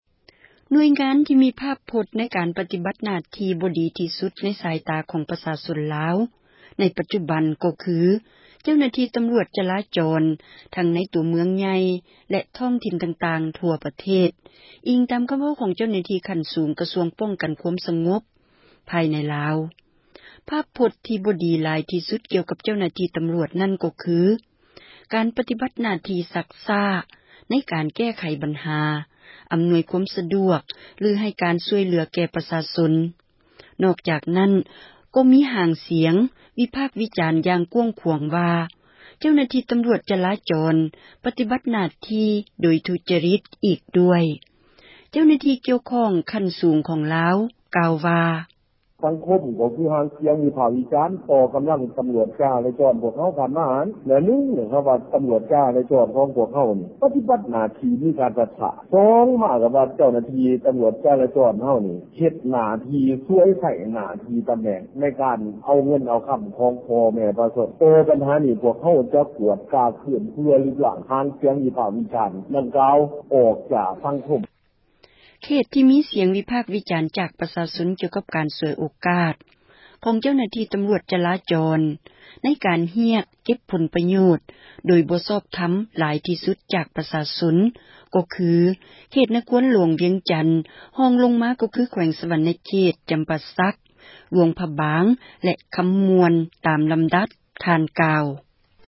ສຽງຕຳຣວດສໍ້ກົງ